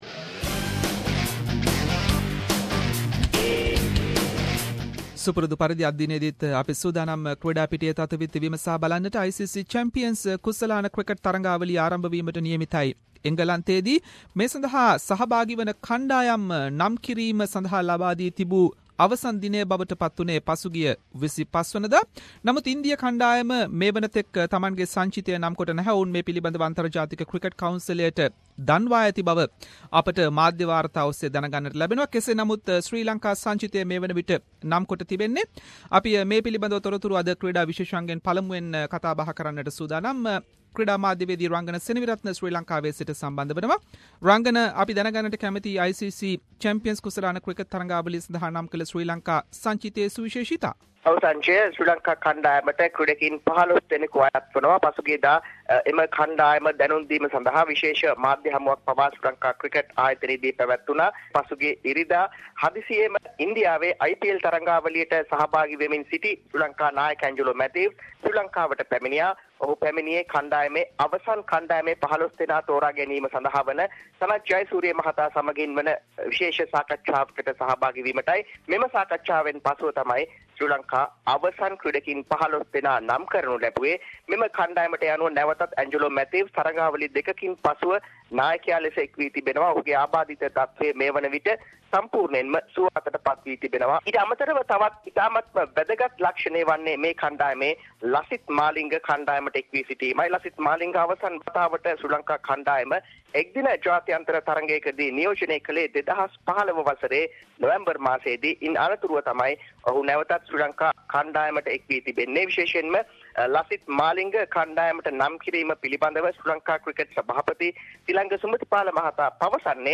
In this weeks SBS Sinhalese sports wrap…. Dilantha Malagamuwas victory at Lamborghini Super Trofeo race held in Italy - Monza, Sri Lanka squad for 2017 ICC champions trophy, Asian Badminton championship - Sri Lanka team news, The Nine Network is being urged by bankers to end its long-time cricket coverage and many more local and international sports news. Sports journalist